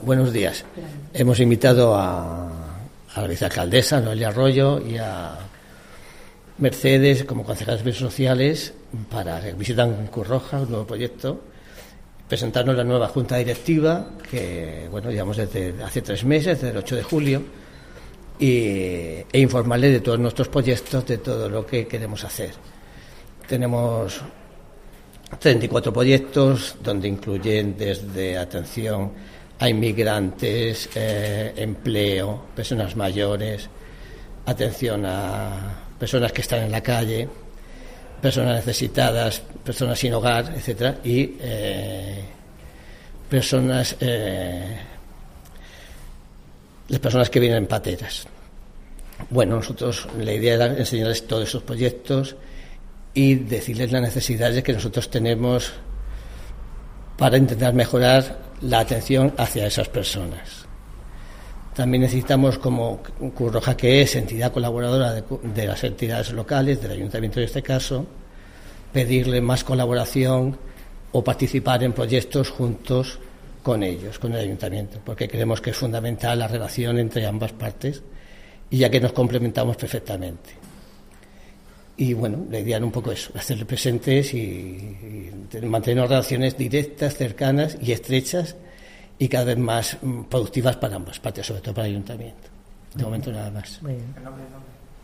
Audio: Vicealcaldesa Noelia Arroyo sobre la reuni�n de la Nueva Junta directiva Cruz Roja (MP3 - 643,39 KB)